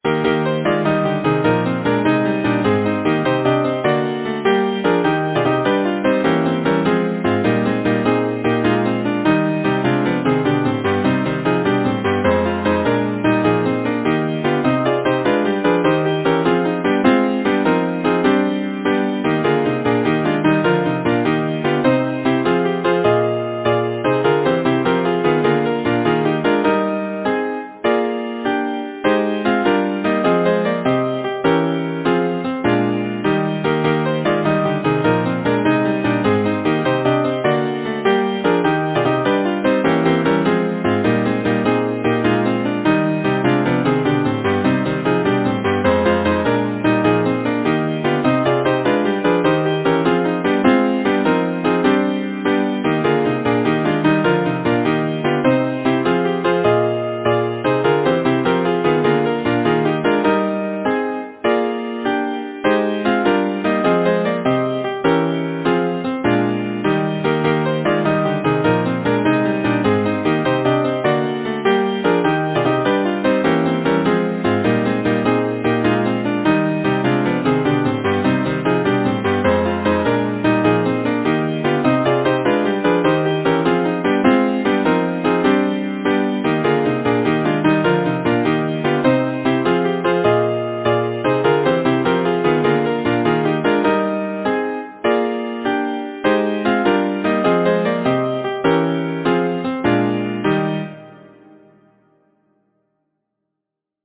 Title: The Brook Composer: Arnold Duncan Culley Lyricist: Alfred Tennyson Number of voices: 4vv Voicing: SATB Genre: Secular, Partsong
Language: English Instruments: A cappella